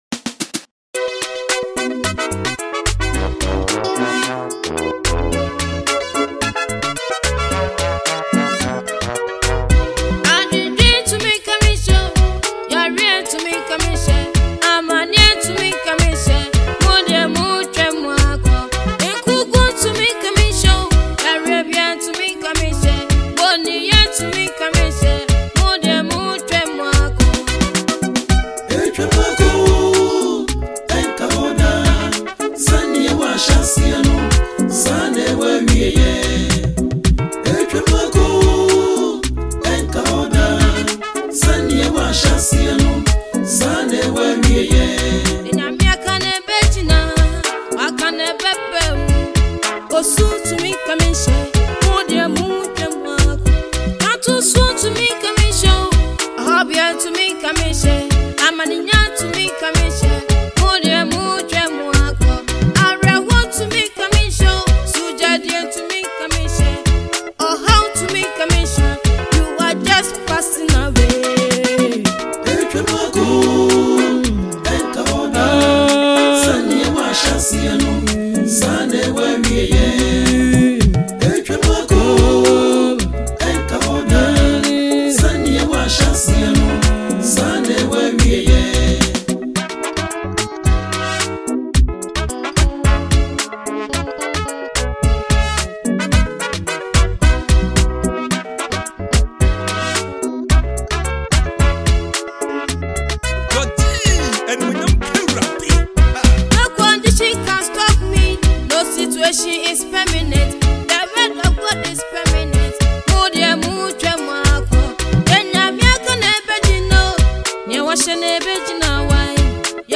Ghanaian Gospel singer-songwriter
This soulful track showcases
With its catchy melody and heartfelt lyrics